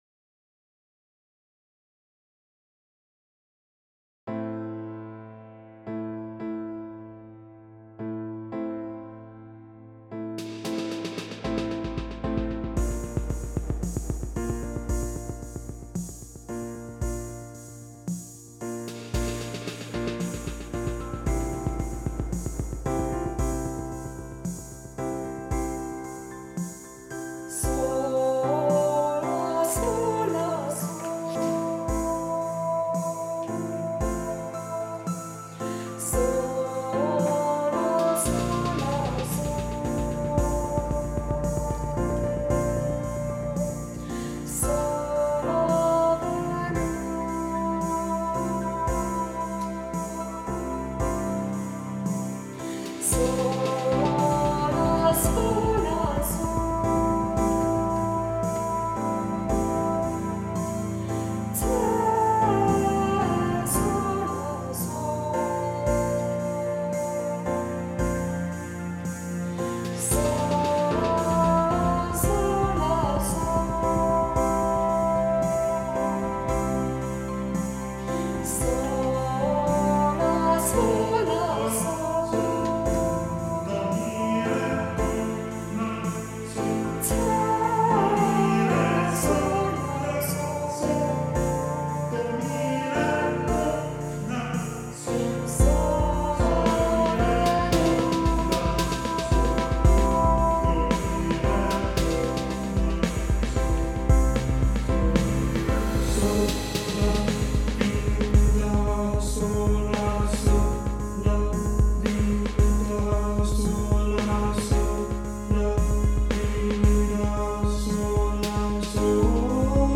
I usually write the music and play the instruments; he sings, writes the singing tune and the lyrics:  Try these